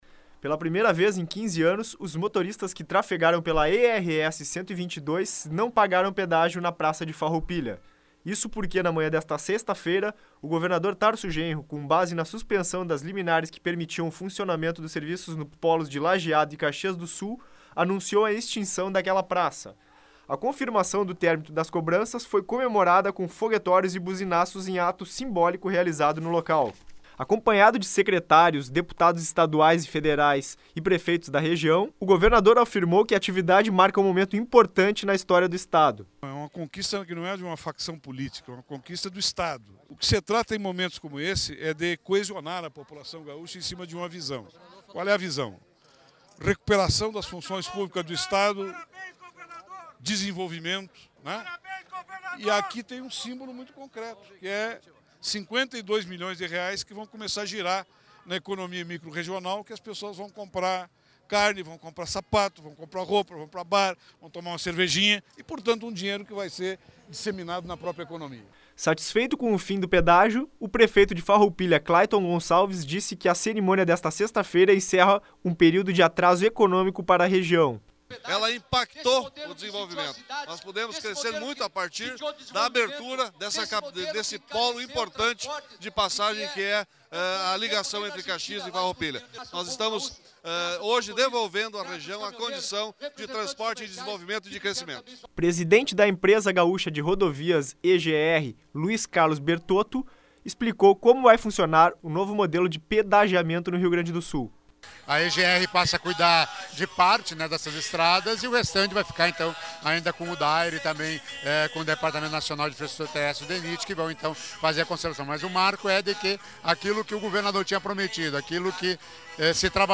Buzinaços, foguetórios e discursos de entidades representativas da sociedade da Serra marcaram o ato simbólico de extinção da praça de pedágios de Farroupilha, na ERS-122, nesta sexta-feira (31).
boletim-pedagio-farroupilha.mp3